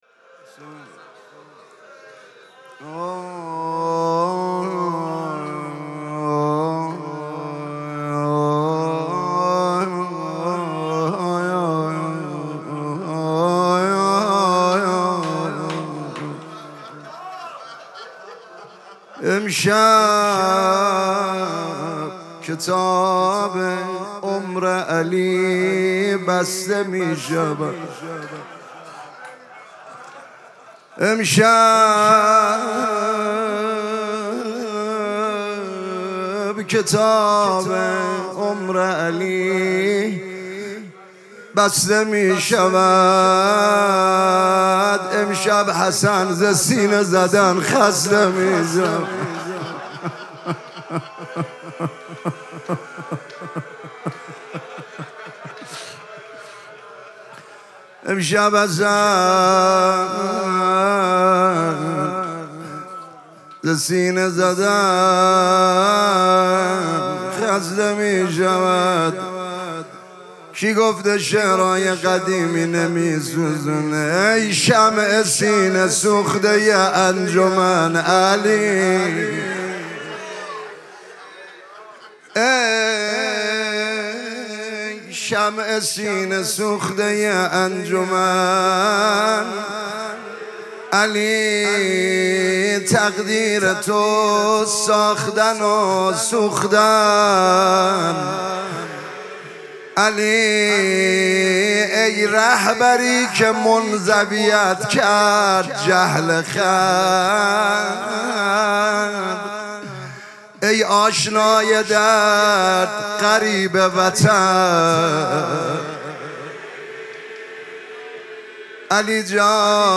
مراسم مناجات شب بیست و دوم ماه مبارک رمضان
روضه
مداح